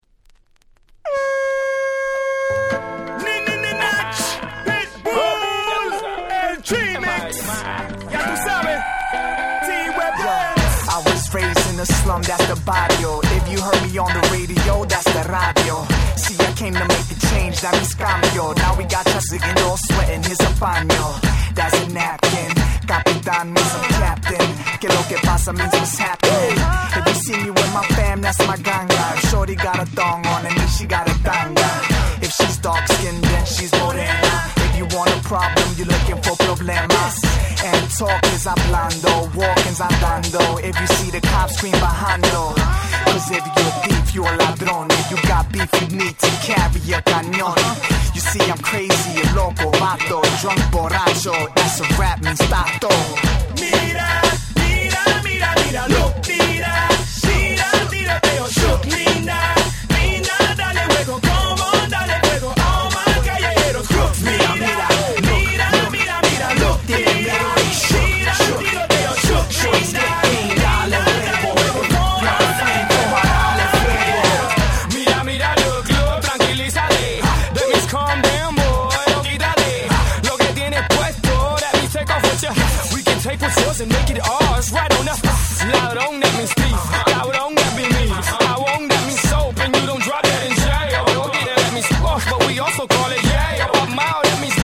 04' Smash Hit Reggaeton !!